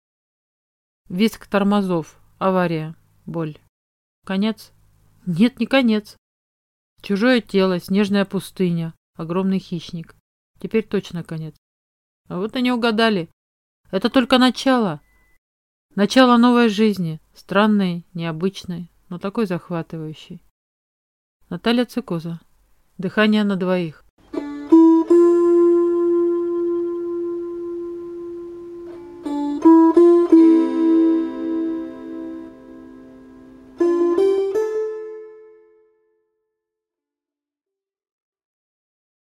Аудиокнига Дыхание на двоих | Библиотека аудиокниг